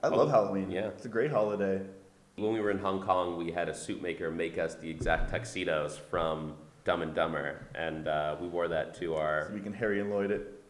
Die Jungs von „Imagine Dragons“ sind ganz große Fans des Feiertags und haben sich dafür sogar extra ihre Kostüme maßanfertigen lassen, wie Schlagzeuger Daniel Platzman und Bassist Ben McKee im Interview erzählen: